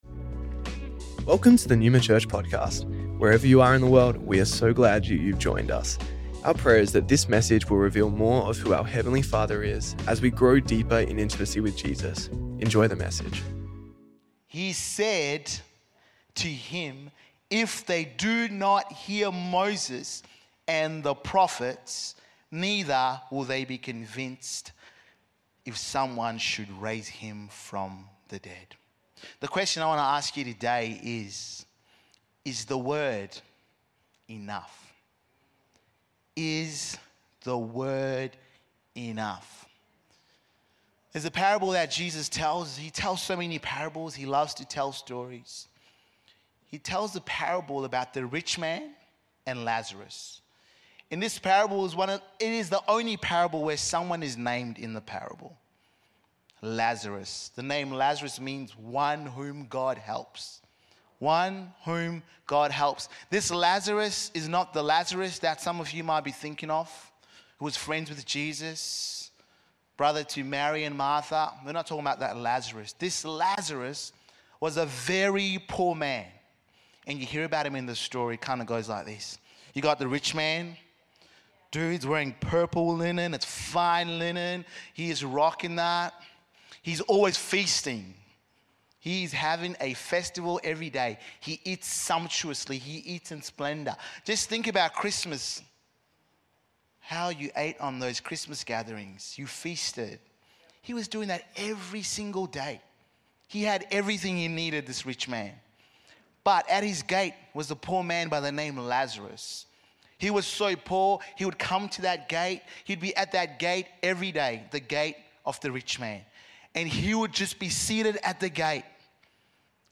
Originally Recorded at the 10AM service on Sunday 12th January 2025&nbsp